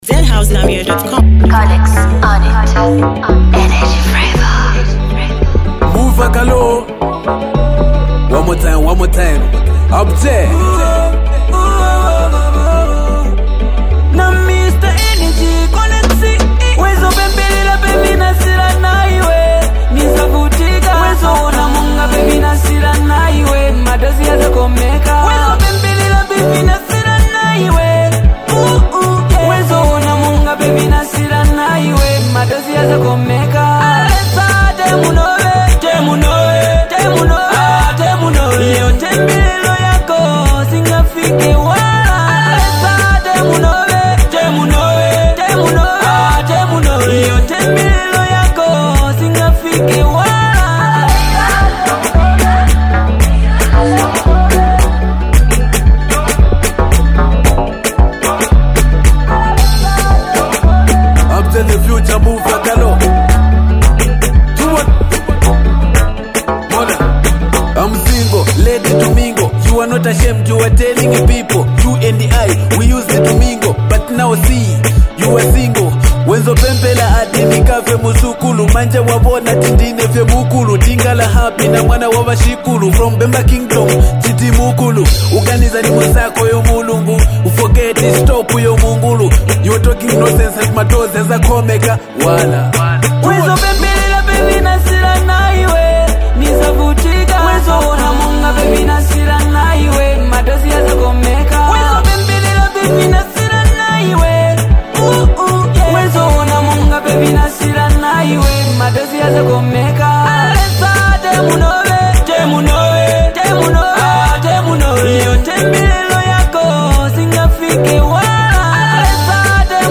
” a heartfelt anthem of gratitude and faith